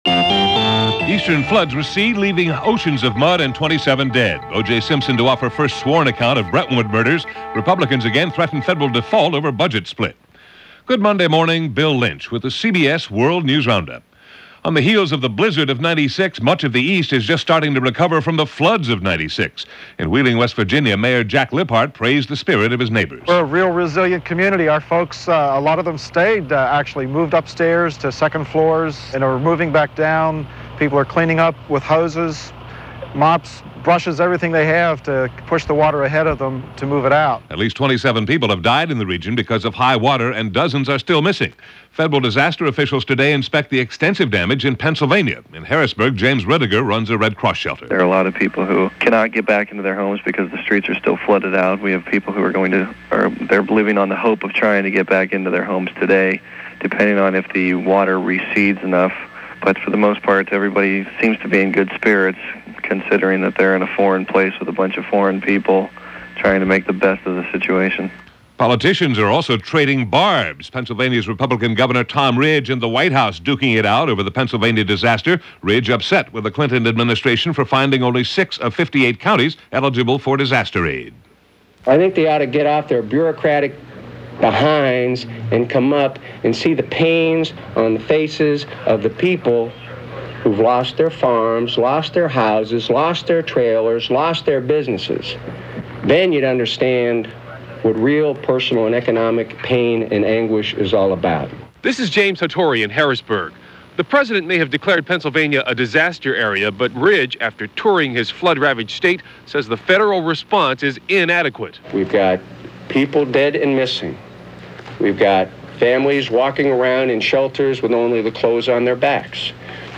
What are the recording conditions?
All that and The Golden Globes too, for this January 22, 1996 as presented by The CBS World News Roundup.